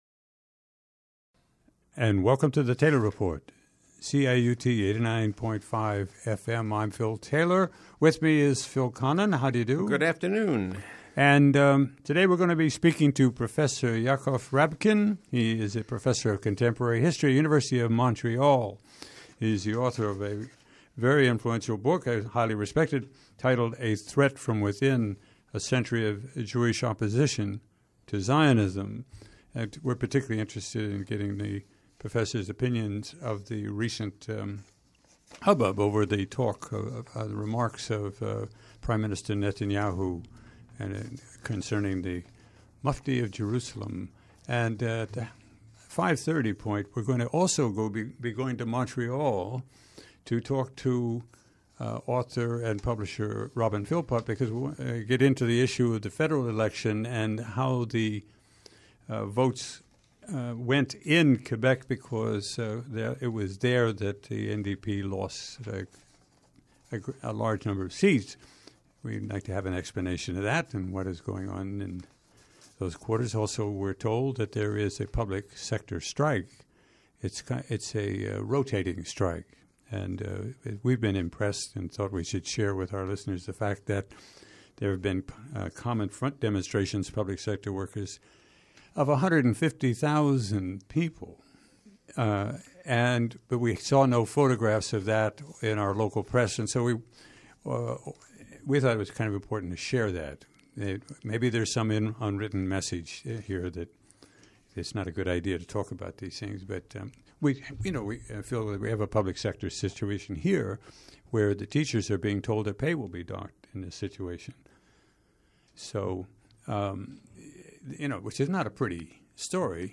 Program Type: Interview